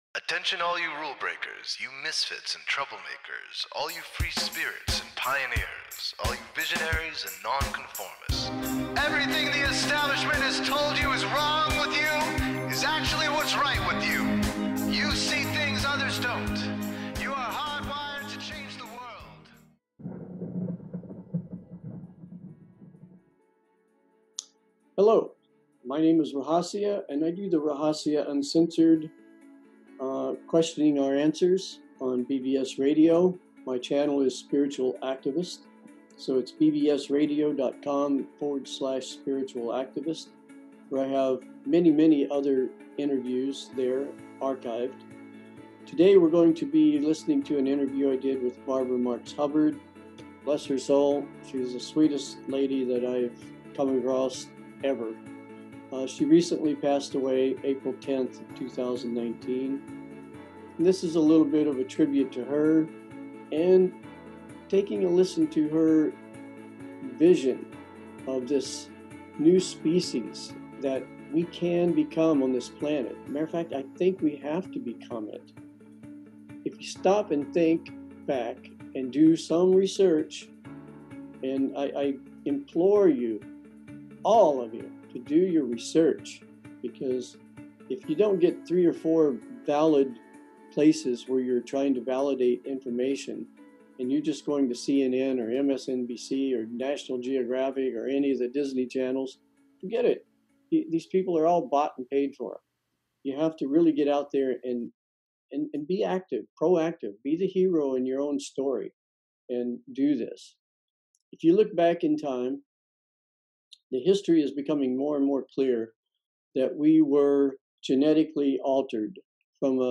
I feel fortunate to have been able to interview Barbara Marx Hubbard for many reasons